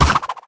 snd_boomerang.ogg